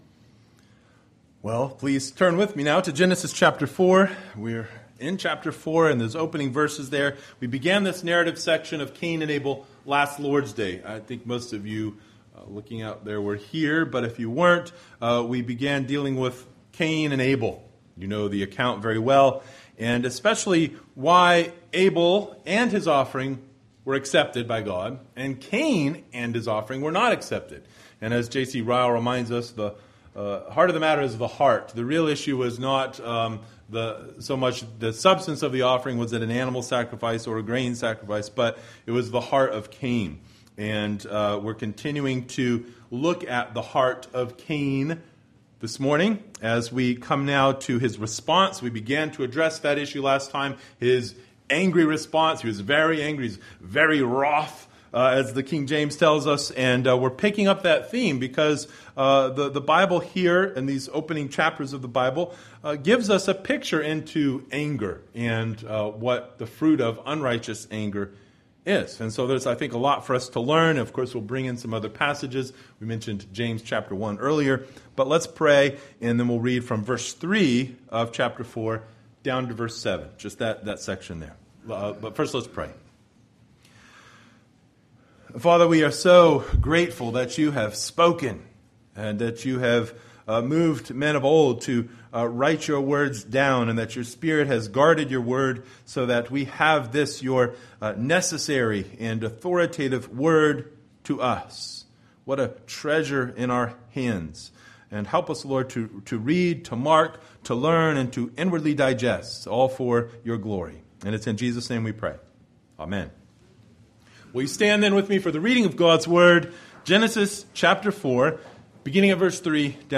Genesis 4:3-7 Service Type: Sunday Morning Bible Text